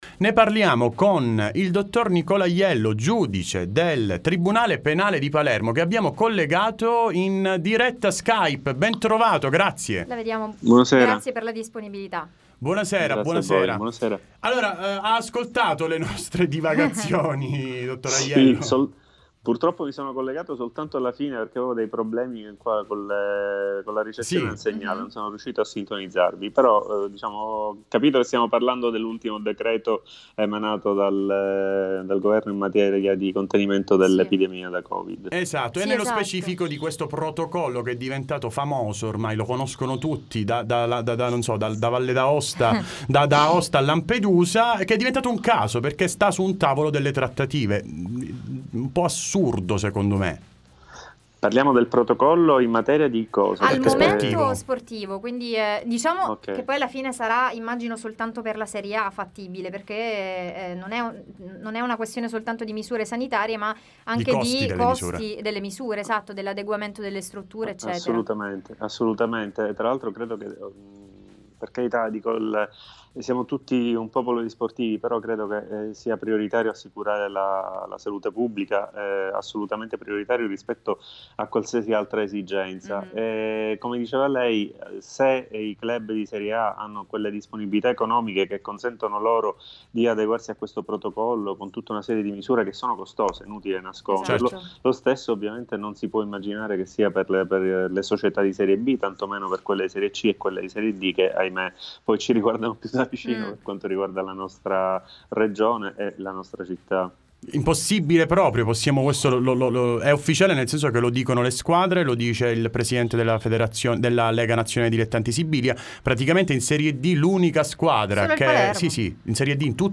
Time Sport Intervista